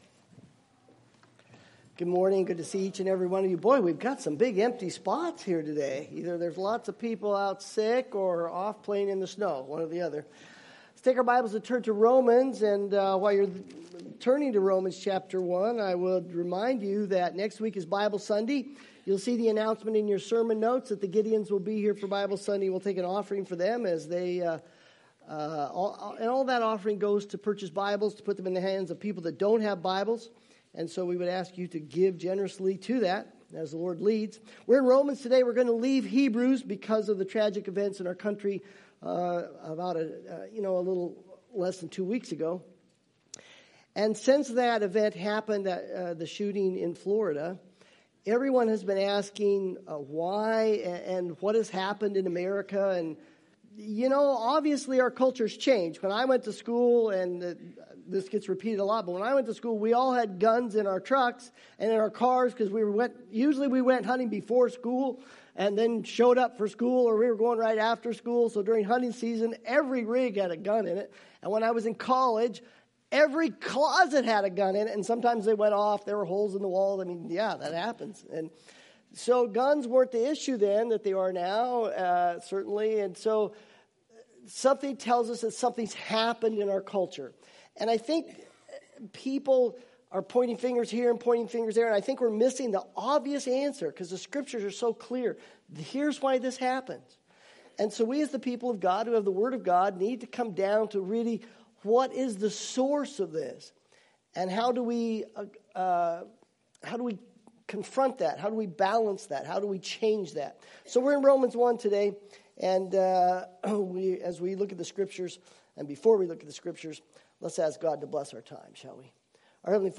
Service Type: Sunday Service Preacher